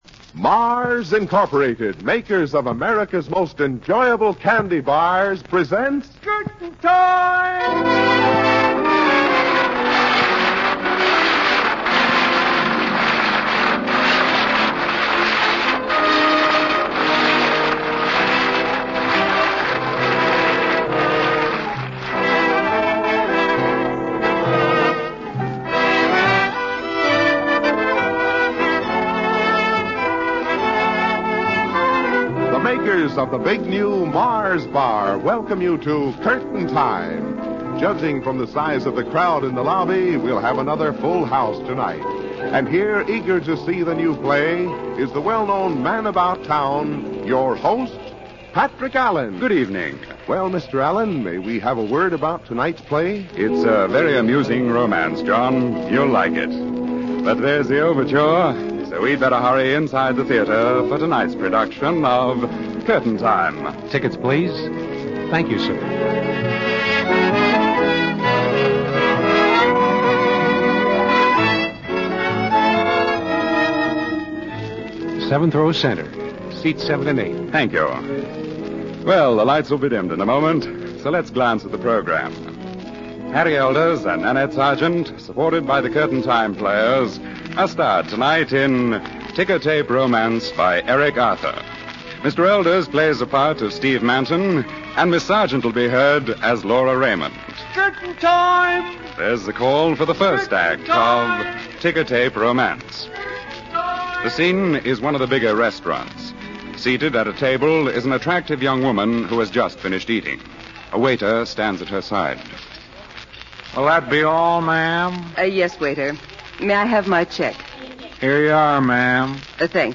Curtain Time was a popular American radio anthology program that aired during the Golden Age of Radio. It was known for its romantic dramas and its unique presentation style that aimed to recreate the atmosphere of attending a live theater performance.Broadcast History: 1938-1939: The show first aired on the Mutual Broadcasting System from Chicago. 1945-1950: It had a much more successful run on ABC and NBC, gaining a wider audience and greater popularity. Format and Features: "Theater Atmosphere": The show used sound effects and announcements to evoke the feeling of being in a theater, with an announcer acting as an usher and reminding listeners to have their tickets ready.